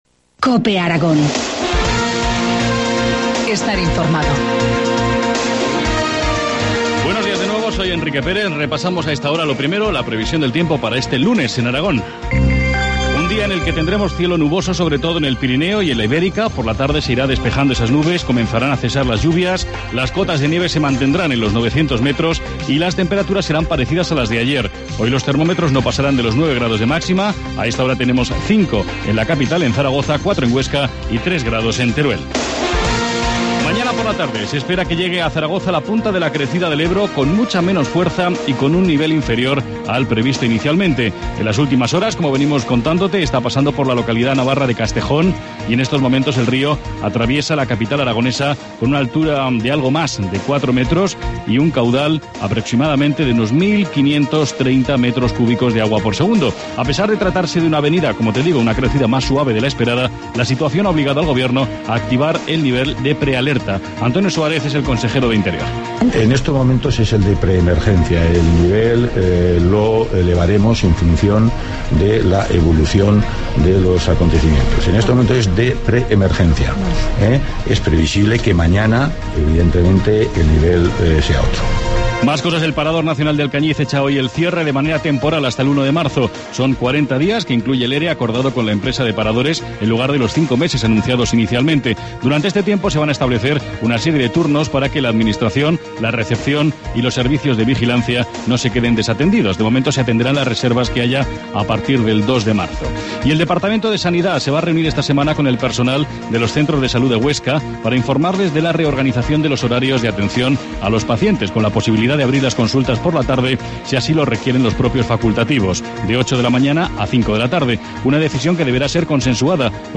Informativo matinal, lunes 21 de enero, 8.25 horas